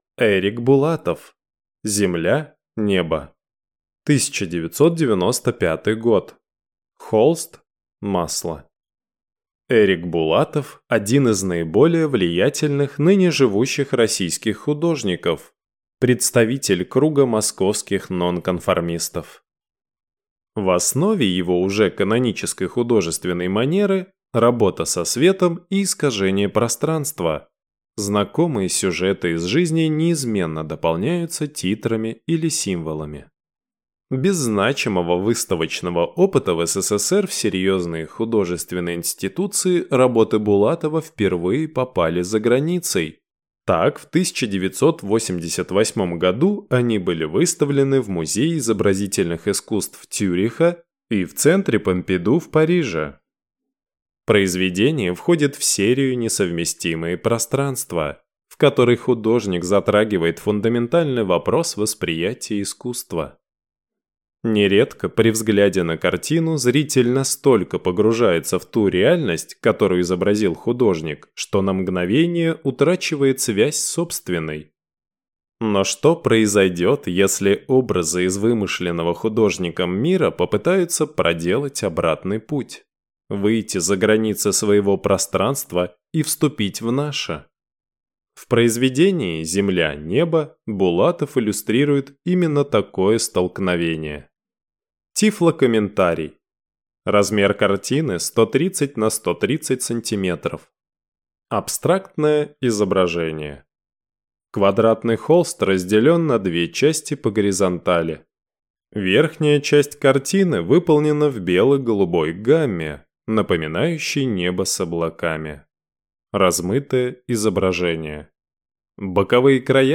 Тифлокомментарий к картине Эрика Булатова "Земля-Небо"